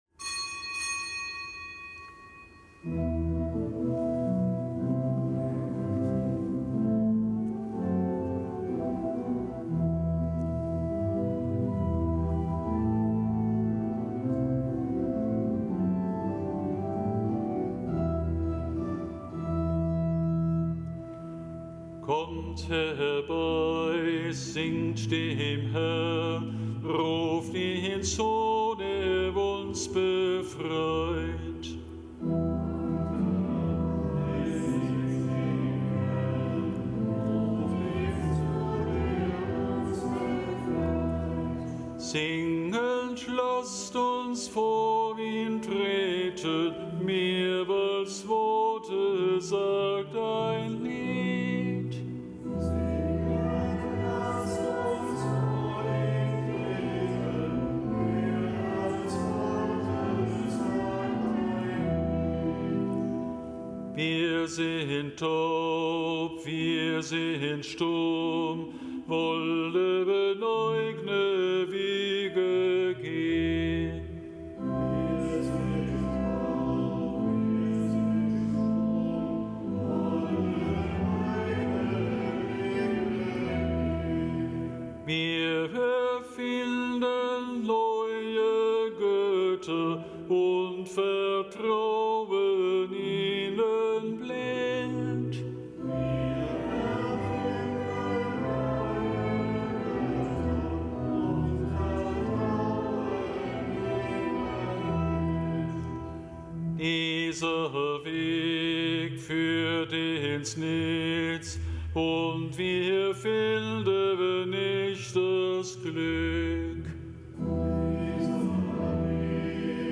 Kapitelsmesse am Mittwoch der 23. Woche im Jahreskreis
Kapitelsmesse aus dem Kölner Dom am Mittwoch der 23. Woche im Jahreskreis.